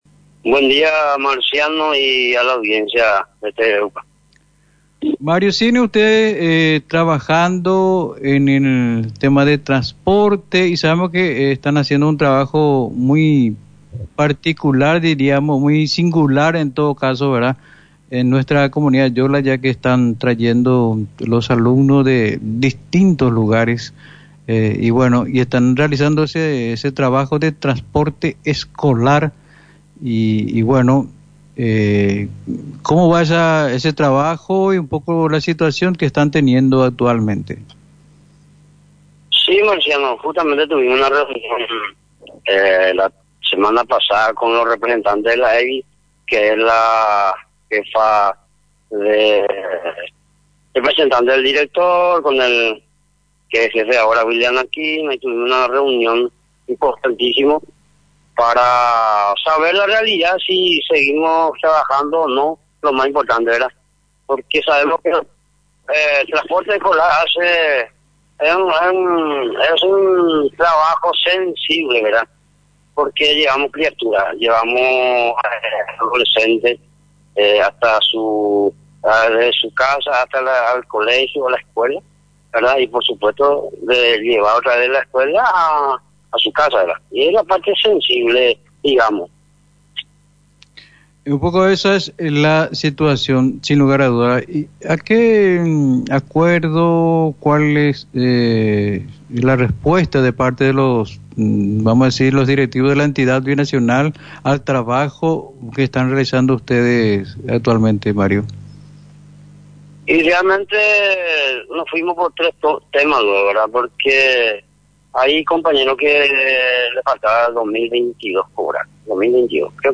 En diálogo con Misión FM